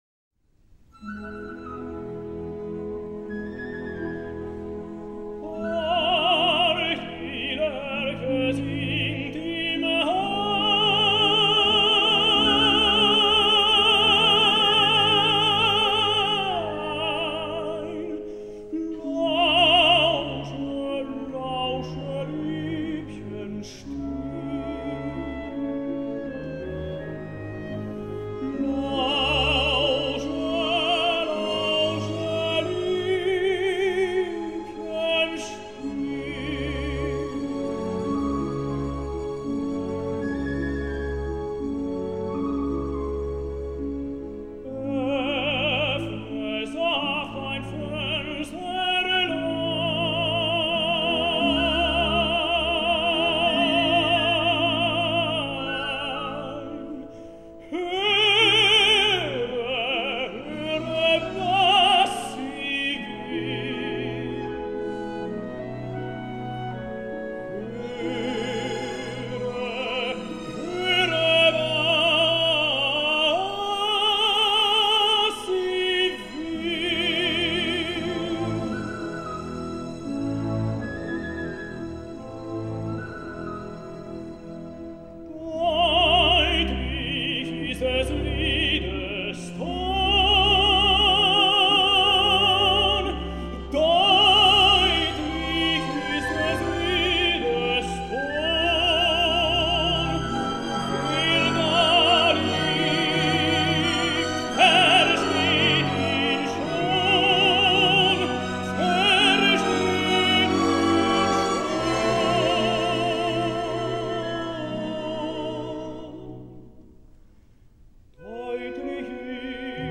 Roberto Sacca, Tenor. Otto Nicolai: Horch, die Lerche singt im Hain (Fenton’s aria, Act III).
Orchestre de la Suisse Romande. Armin Jordan, conductor.